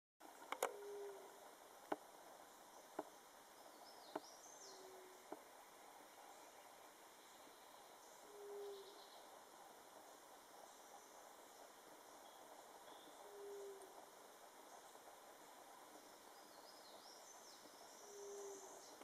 Grey-fronted Dove (Leptotila rufaxilla)
Location or protected area: Parque Provincial Cruce Caballero
Condition: Wild
Certainty: Recorded vocal